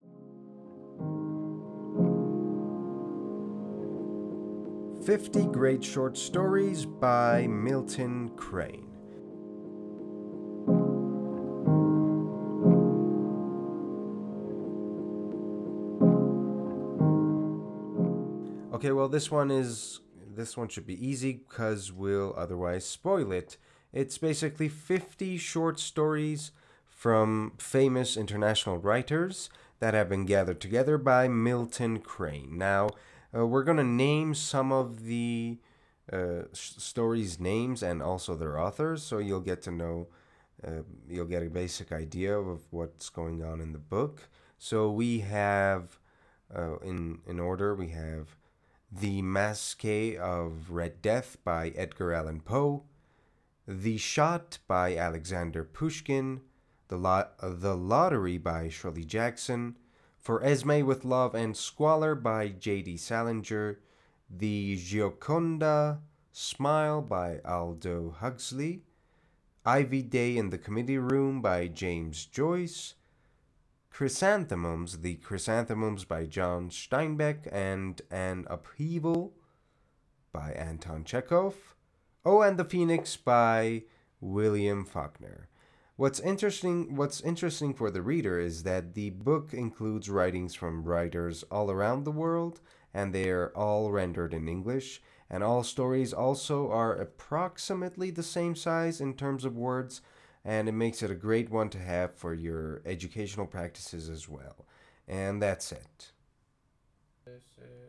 معرفی صوتی کتاب 50 Great Short Stories